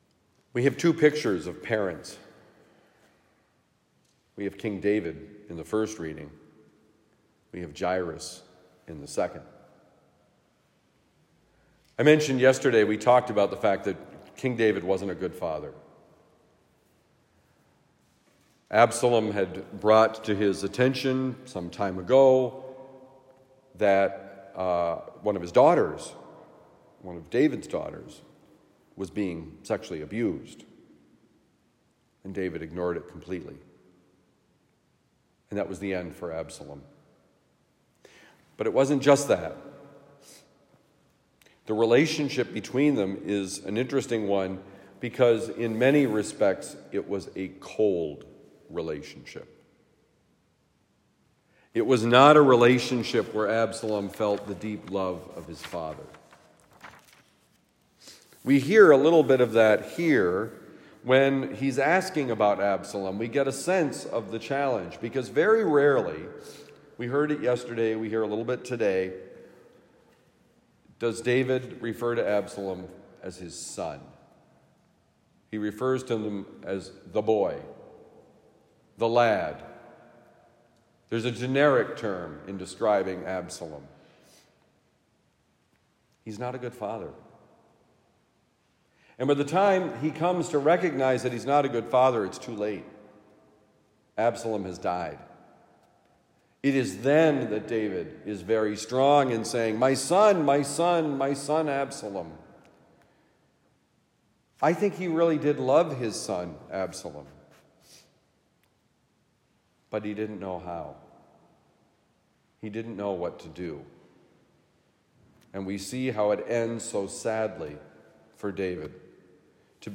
David and Jairus: Homily for Tuesday, January 30, 2024